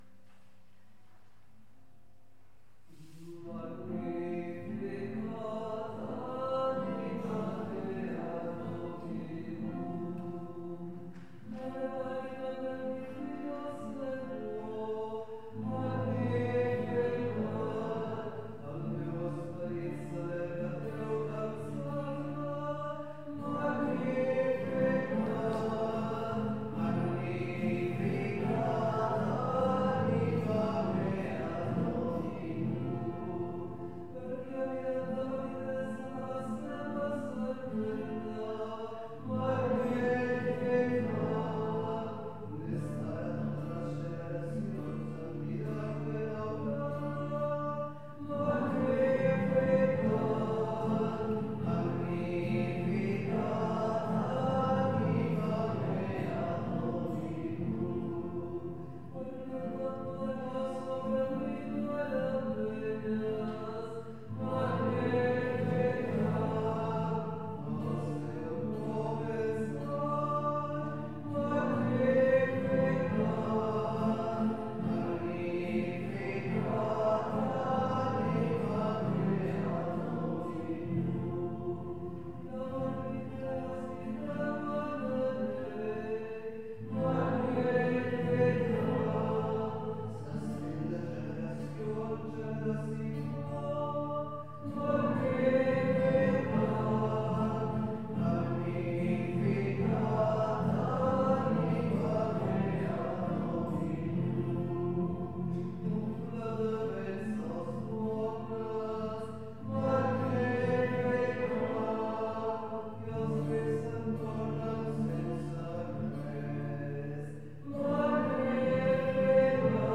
Pregària de Taizé a Mataró... des de febrer de 2001
Convent de la Immaculada - Carmelites - Diumenge 17 de desembre 2017